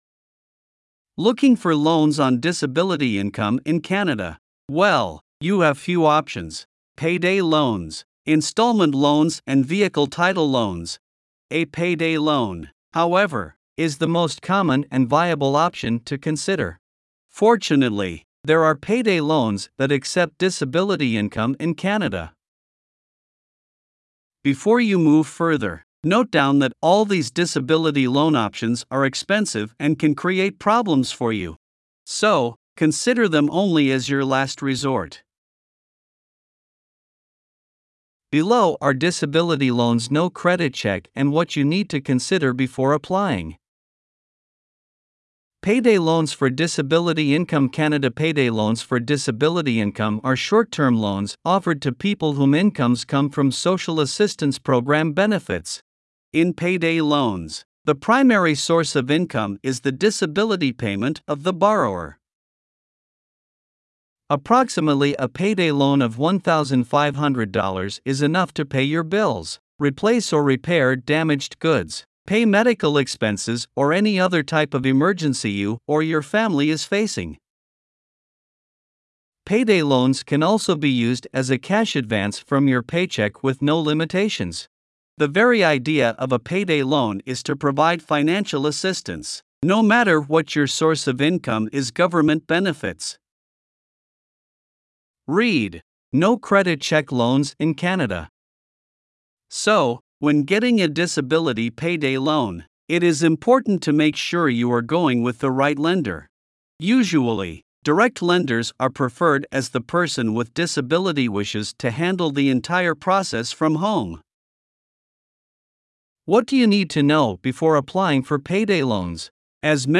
Voiceovers-Voices-by-Listnr_12.mp3